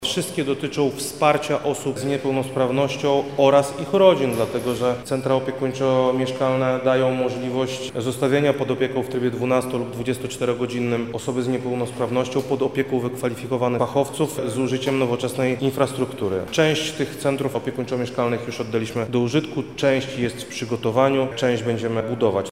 Krzysztof Komorski– mówi Wojewoda Lubelski, Krzysztof Komorski.